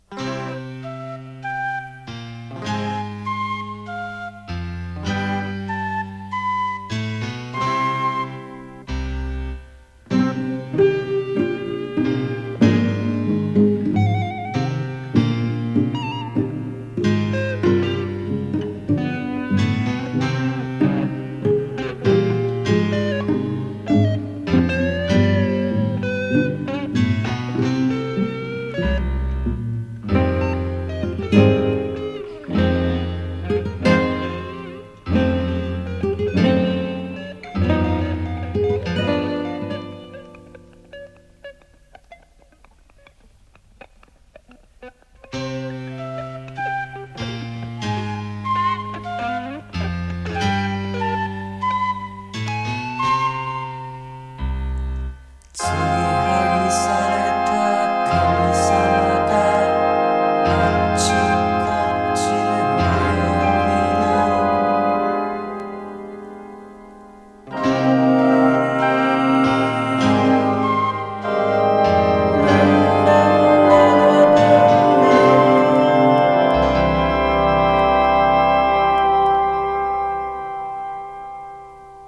NO WAVE / POST PUNK、東京ロッカーズ、あぶらだこ、ゆらゆら帝国好きまで必聴！